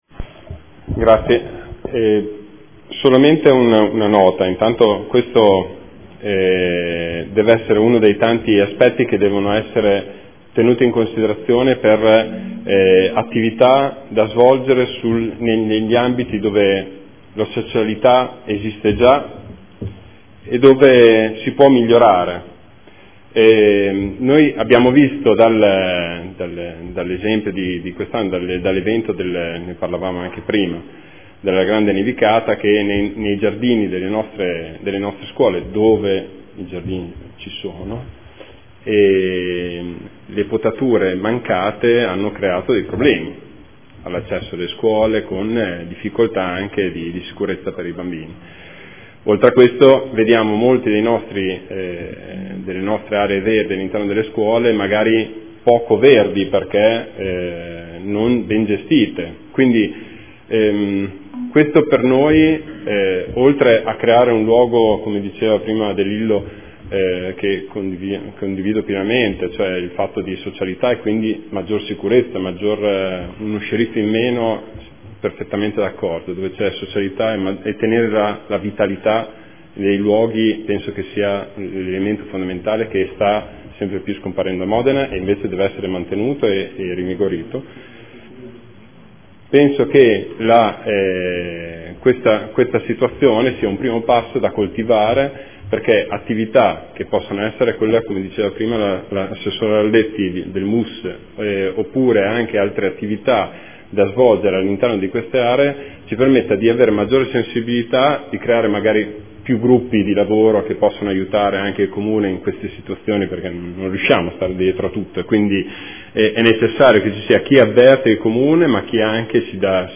Le aree cortilive delle scuole statali dell’infanzia e primarie diventano parchi pubblici dopo le 16,30 offrendosi alla cittadinanza come luoghi di incontro, di socializzazione e di presidio per una maggiore sicurezza e vivibilità dei quartieri”. Dibattito